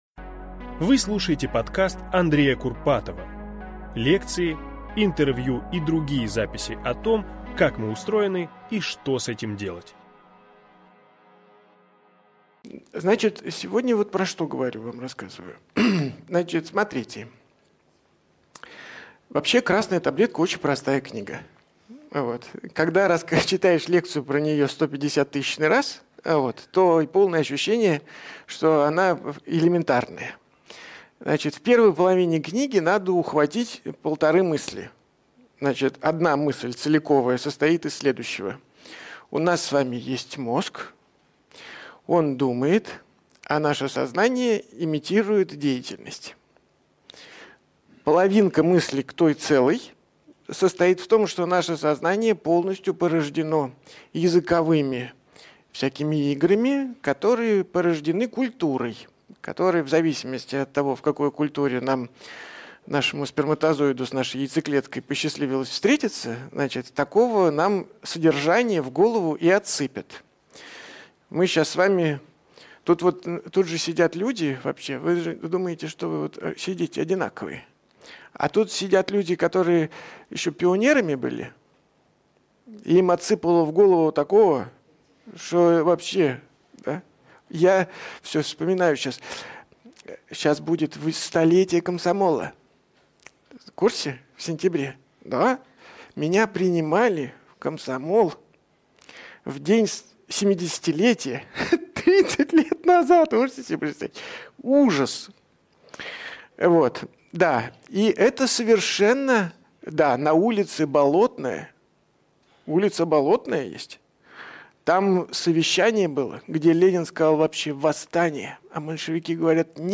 Аудиокнига Инстинкты и потребности. Как их удовлетворить?